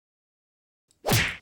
kung fu punch
crack fu kung pow punch punch2 slap smack sound effect free sound royalty free Memes